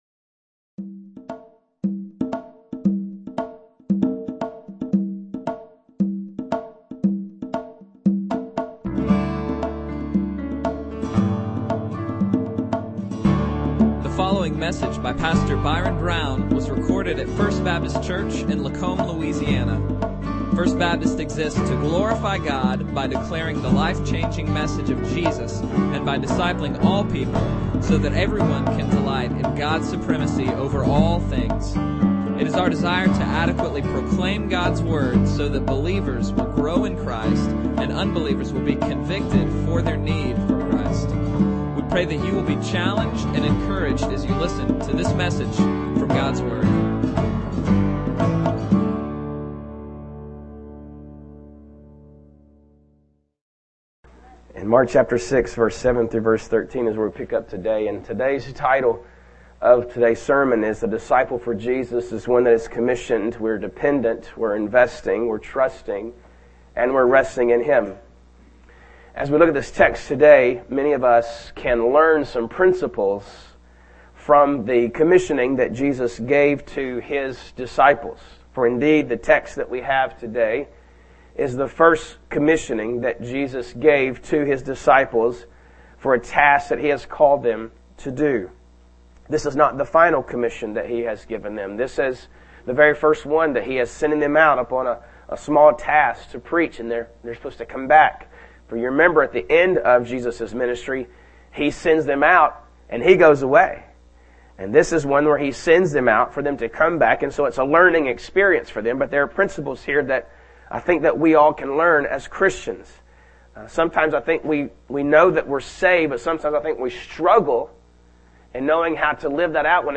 Bible Text: Mark 6:7-13 | Preacher